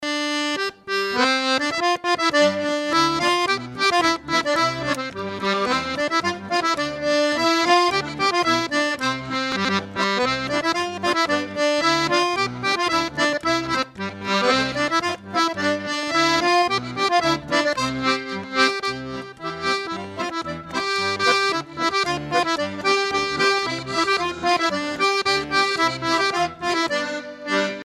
Chants brefs - A danser
Coueff's et Chapias Groupe folklorique
en spectacle